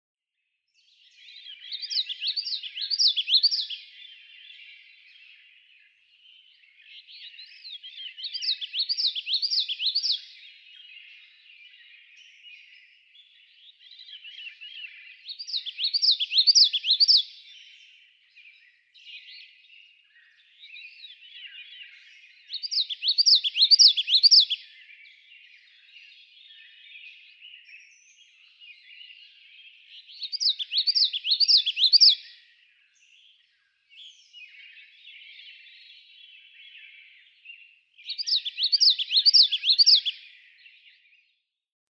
June 3, 2016. Sax-Zim Bog, Minnesota.
♫220, ♫221—longer recordings from those two neighbors
220_Common_Yellowthroat.mp3